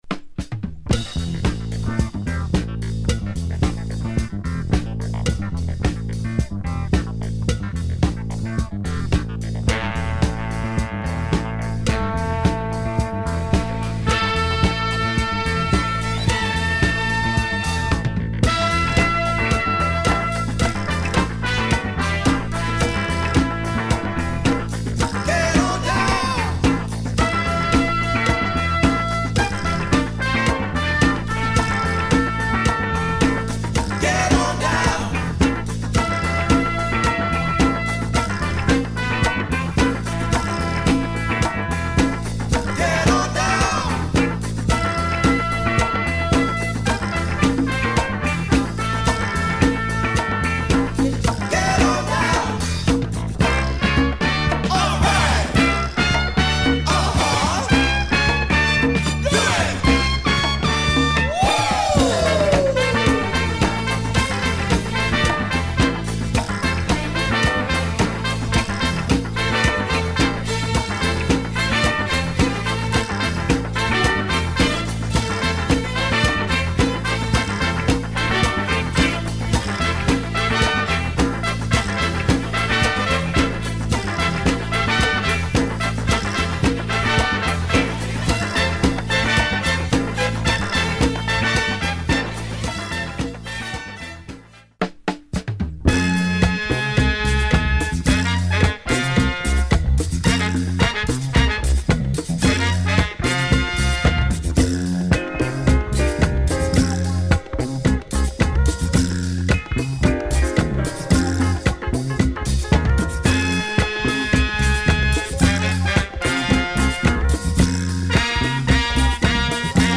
Oh what a cult funk & disco-funk album from New York !
Fire on the dancefloor !